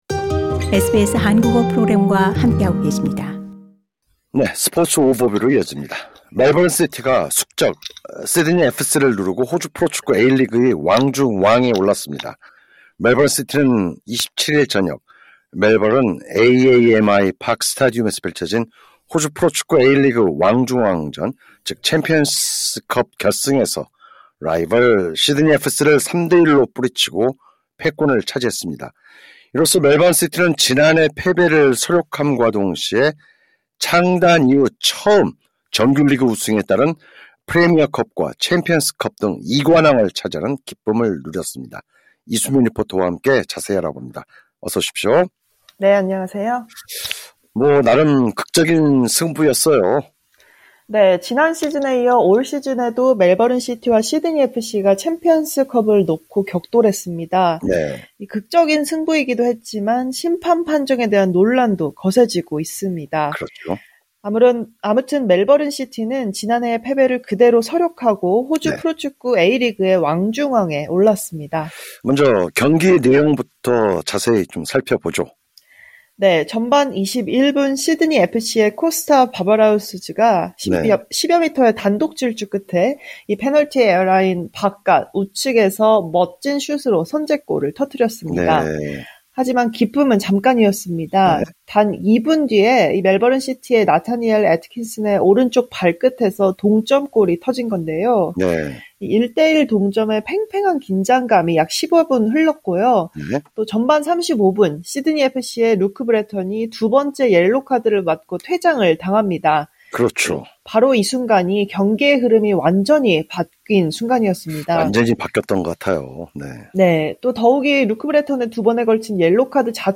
진행자: 먼저 경기 내용부터 살펴보죠.